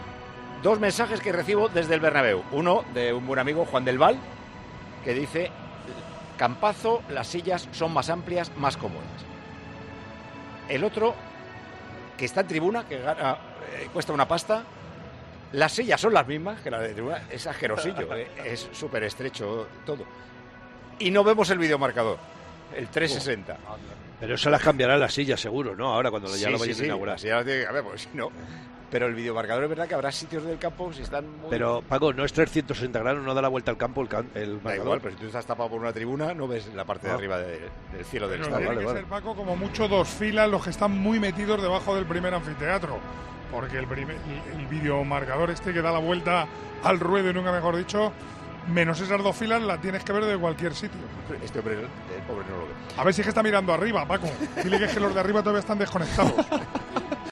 Incluso hay mejoras que dividieron a ciertos aficionados... y que tienen que ver con los asientos y el nuevo videomarcador. Escucha el fragmento de Tiempo de Juego en el que Paco González desvela estos nuevos detalles del nuevo Santiago Bernabéu.
Paco González describe las sensaciones de los aficionados del nuevo Bernabéu